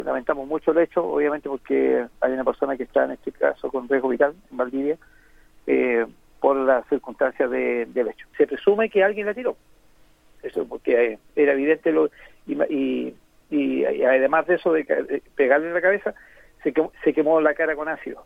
En entrevista con Radio Bío Bío